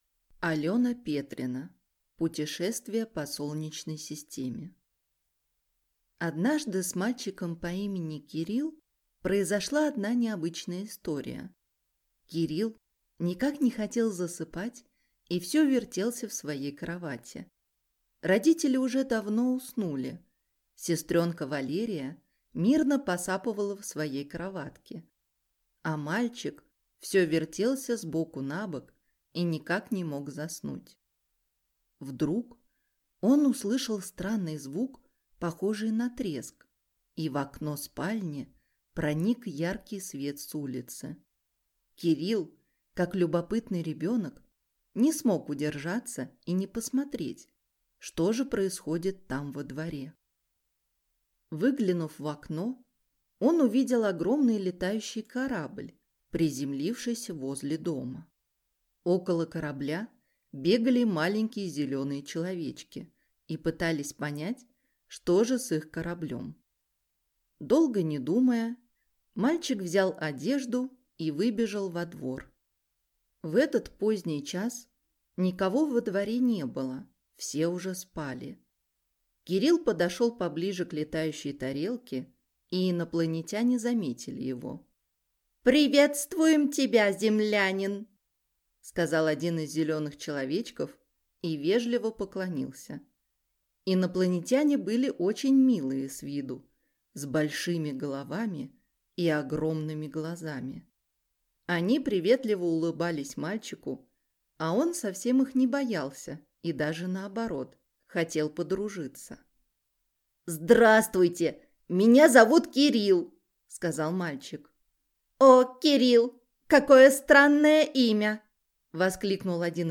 Аудиокнига Путешествие по Солнечной Системе | Библиотека аудиокниг